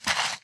pedology_sand_footstep.1.ogg